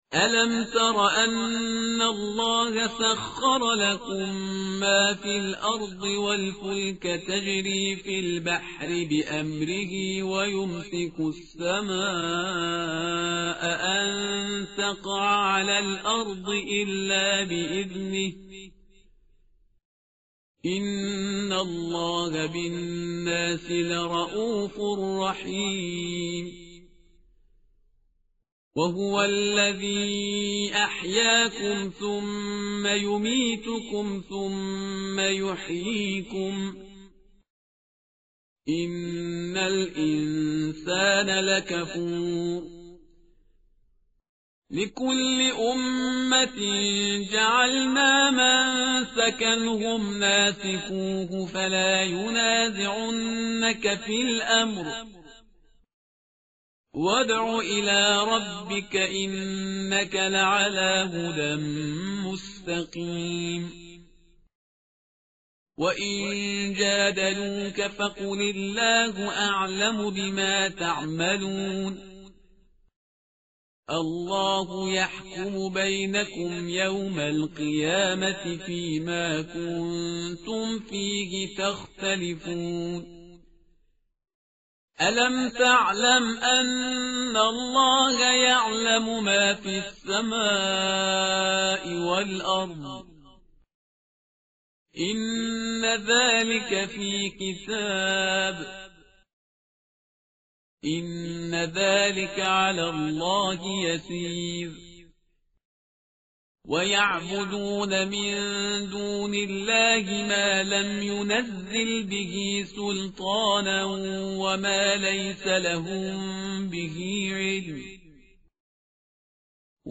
متن قرآن همراه باتلاوت قرآن و ترجمه